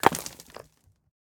stonefail3.ogg